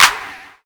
JJClap (12).wav